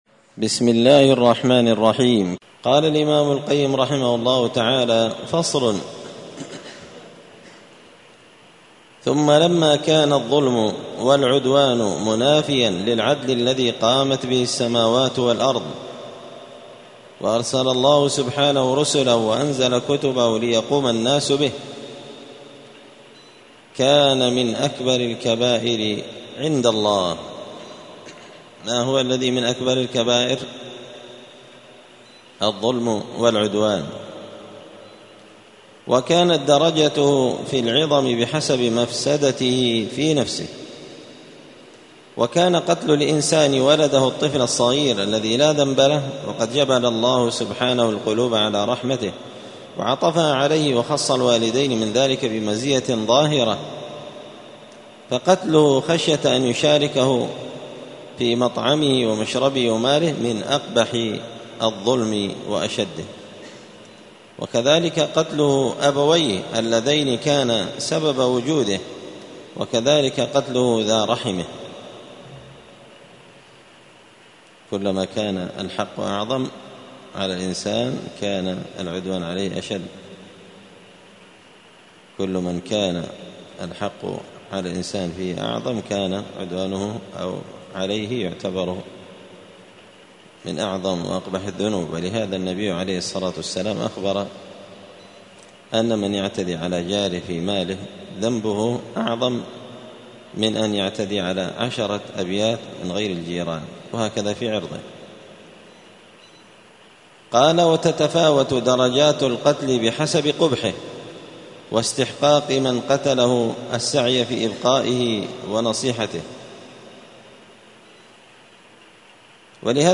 *الدرس الخامس والستون (65) فصل الظلم والعدوان*